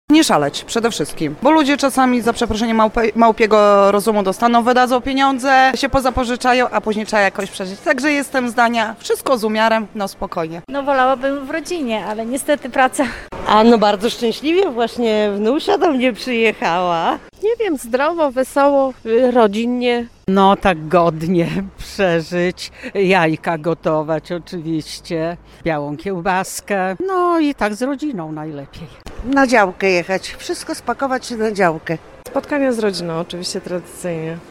Jak spędzimy Wielkanoc? [SONDA]
Jak przeżyć te święta? O to zapytaliśmy mieszkańców Ełku: